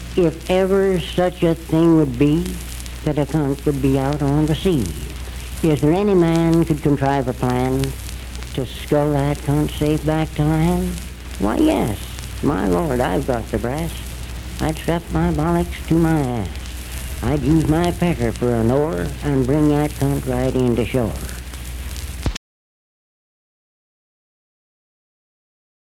Unaccompanied vocal music
Verse-refrain 2(4). Performed in Sandyville, Jackson County, WV.
Bawdy Songs, Folklore--Non Musical
Voice (spoken)